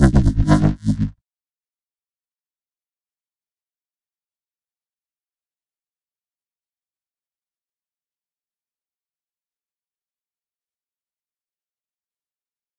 摇摆不定的贝司声 " 贝司重采样1
描述：音乐制作的疯狂低音
Tag: 重采样 重低音 音效设计 摇晃